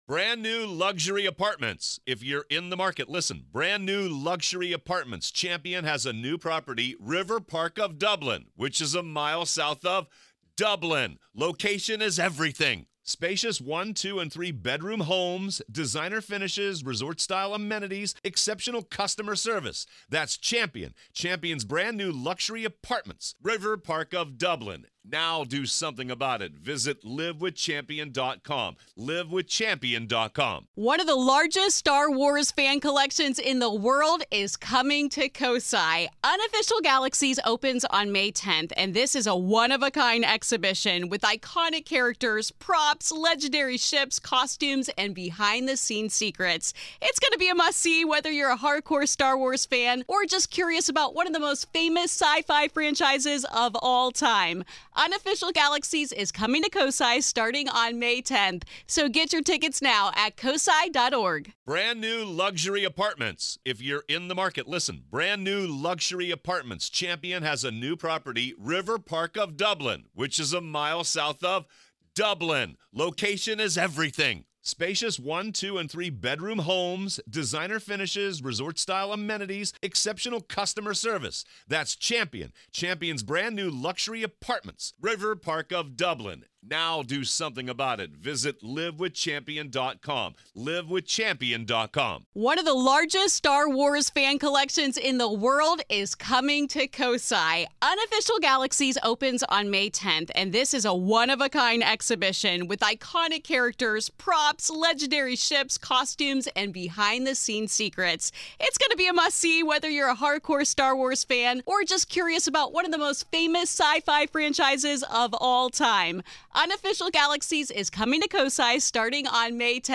Today on The Grave Talks, Part Two of our conversation